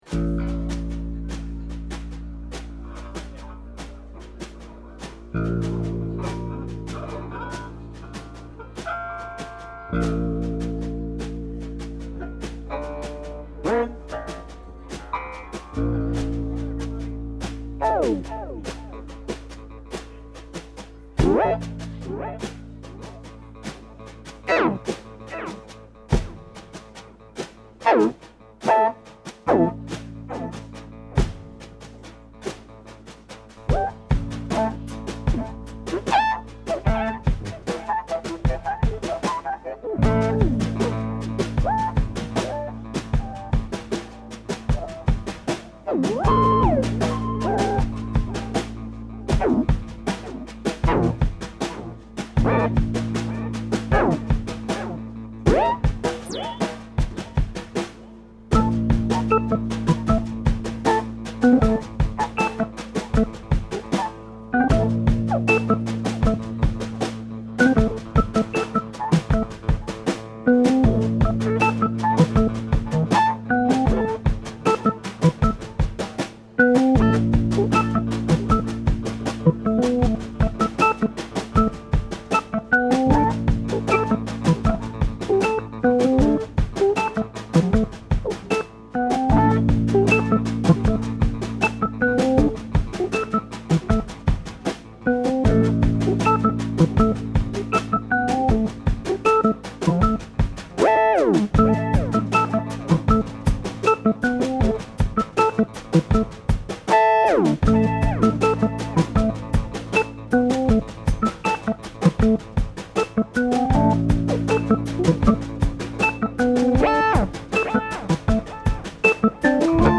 Last Concert Café, 10/23/04
keys
drums
Bass. CD Release concert